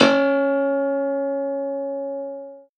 53v-pno03-C2.wav